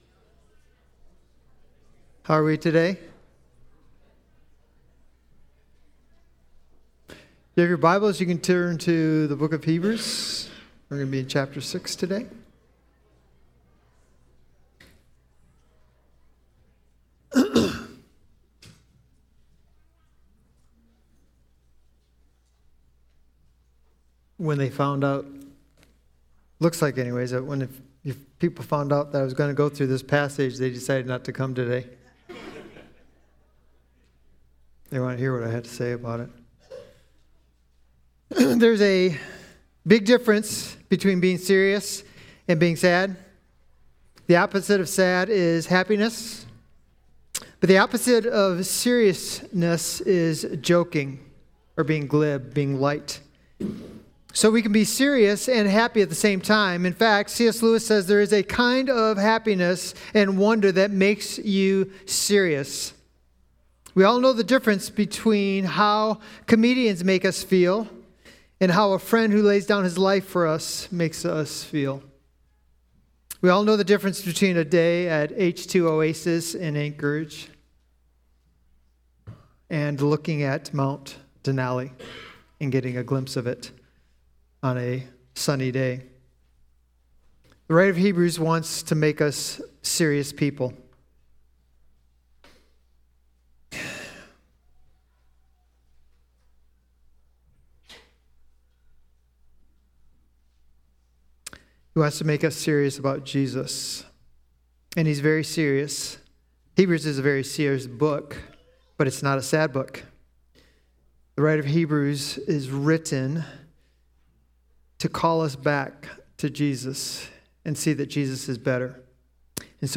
Sermons | Christian Community Church